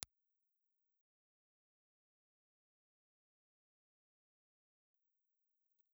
Impulse Response file of Melodium RM6 ribbon microphone in 1m position
Melodium_RM6_IR.wav